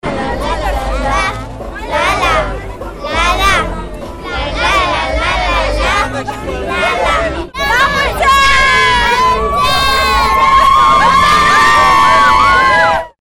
După acest moment, pe scenă a urcat Smiley, primit în aplauzele tuturor: